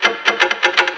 6 Pastel Guitar Long.wav